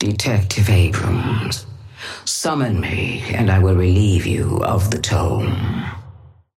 Sapphire Flame voice line - Detective Abrams.
Patron_female_ally_atlas_start_01.mp3